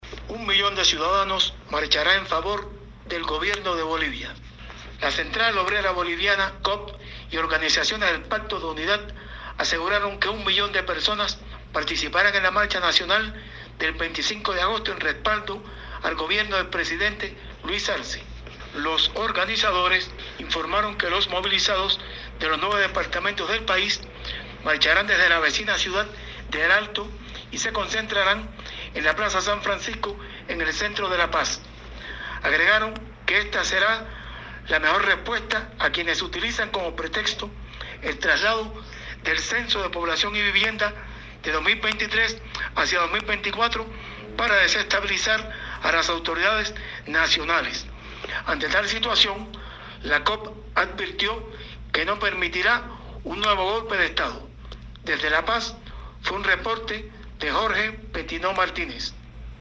desde La Paz.